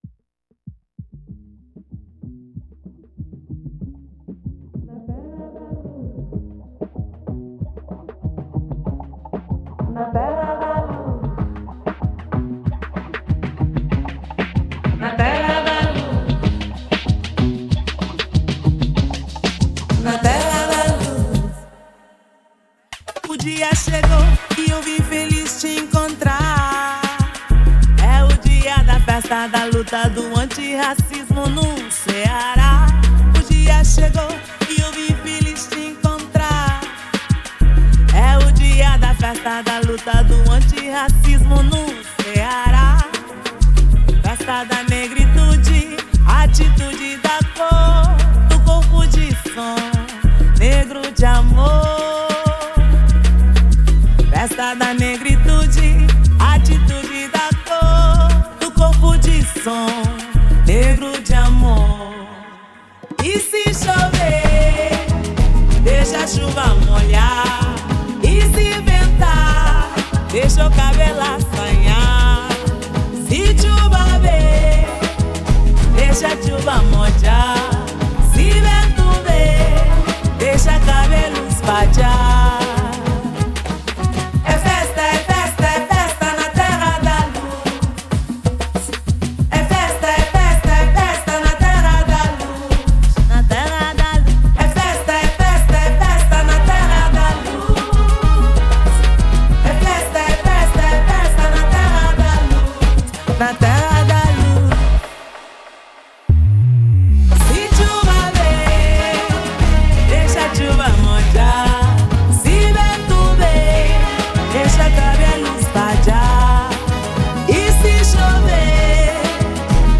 (com trecho em crioulo)
Voz
em fusão de funaná lento com samba-reggae